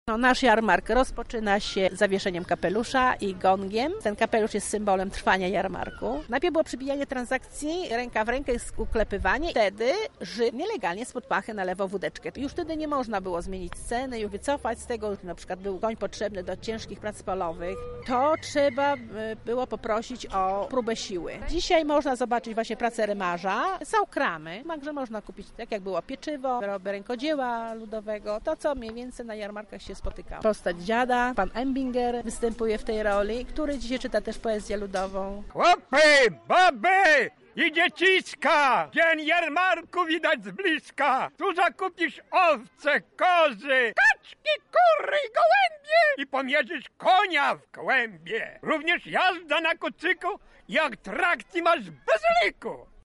W Muzeum Wsi Lubelskiej odbyła się 22 edycja jarmarku końskiego.
Na miejscu była nasza reporterka: